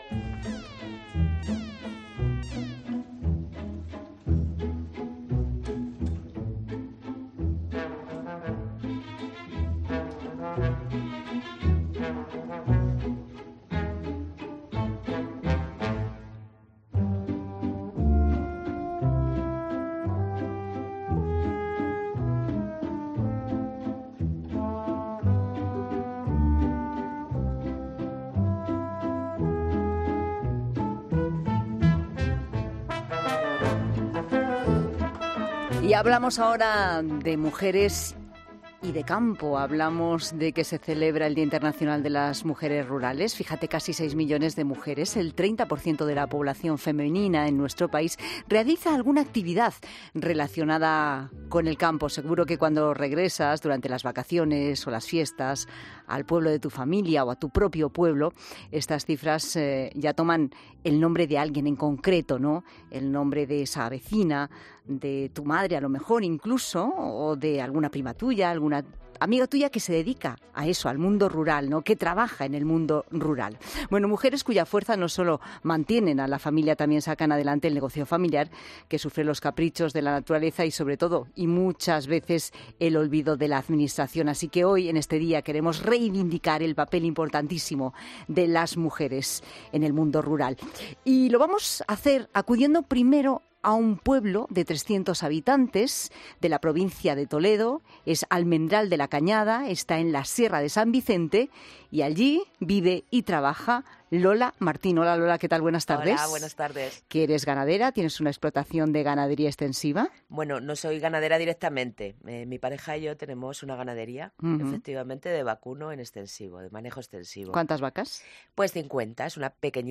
En La Tarde hablamos del Día Mundial de la Mujer Rural con dos ganadera